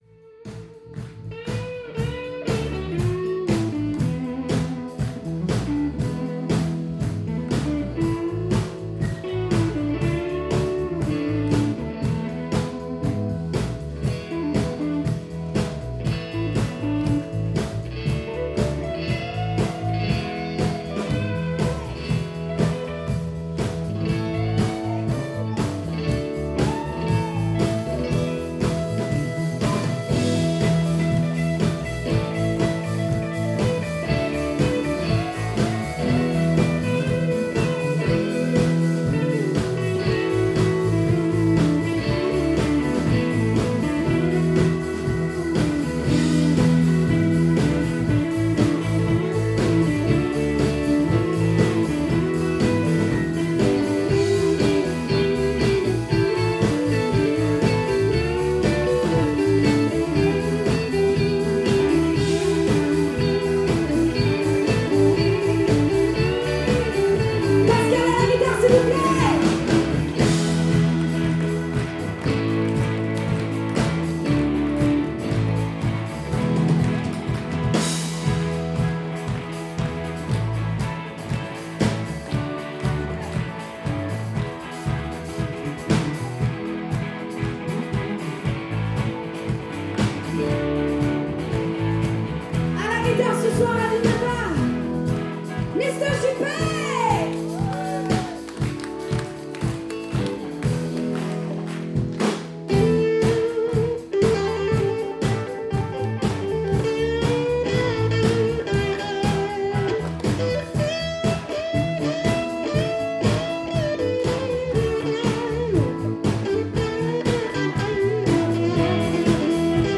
Lors d'un final époustouflant, les musiciens présents sur scène
ont joué à tour de rôle des soli plus jolis les uns que les autres sur une trame exercée par la rythmique basse/batterie